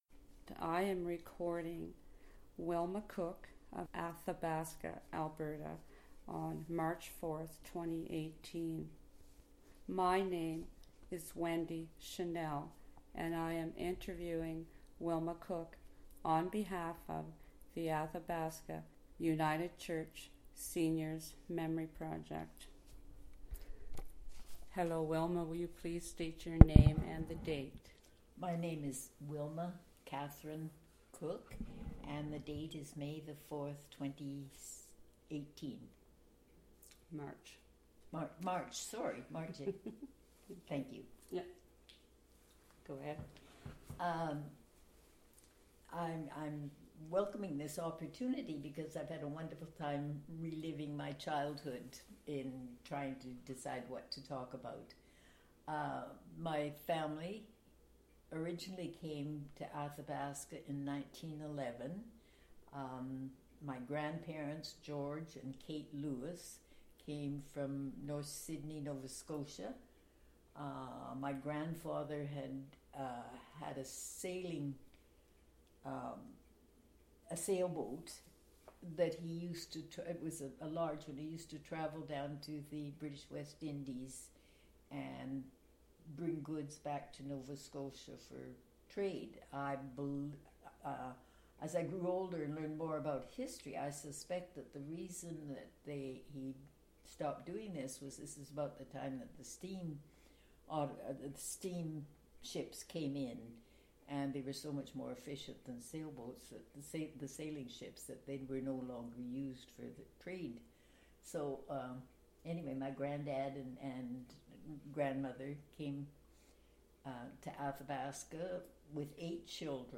Audio interview and obituary,